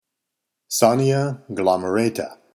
Pronunciation/Pronunciación:
Són-ne-a glo-me-rà-ta